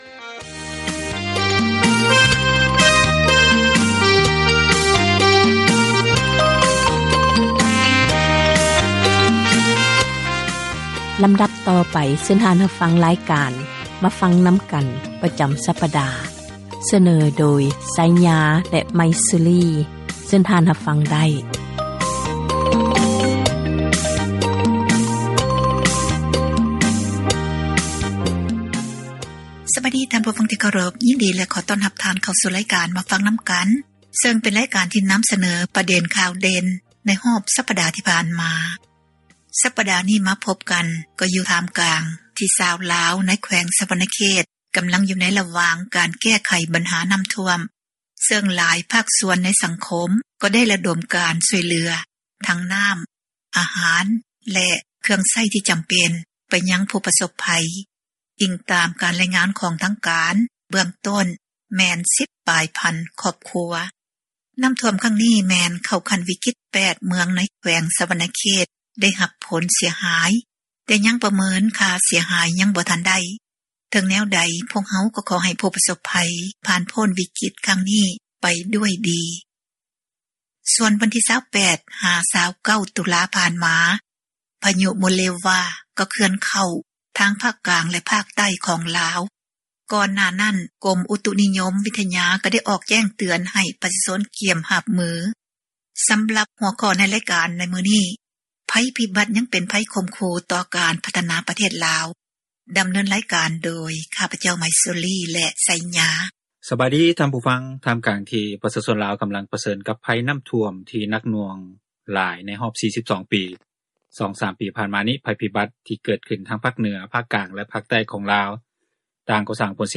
ແມ່ນຣາຍການສົນທະນາ ບັນຫາສັງຄົມ ທີ່ຕ້ອງການ ພາກສ່ວນກ່ຽວຂ້ອງ ເອົາໃຈໃສ່ແກ້ໄຂ,